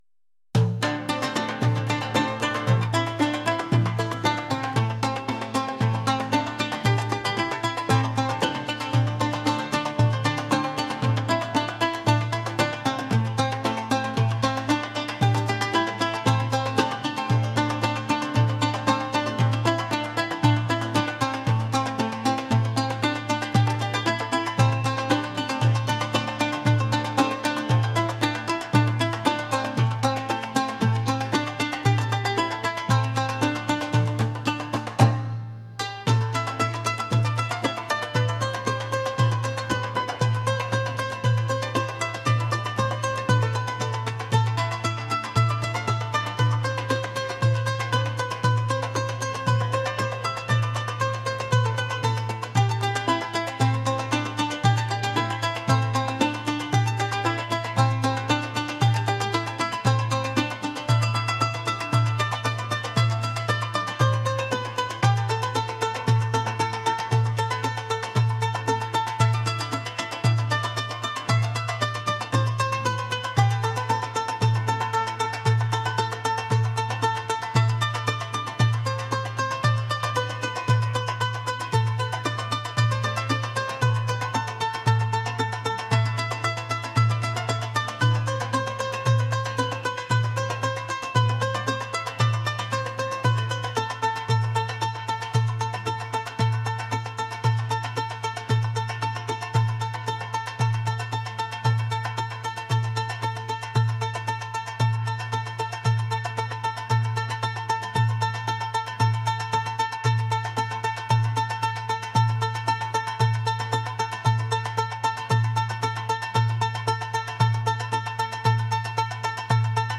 rhythmic | traditional | world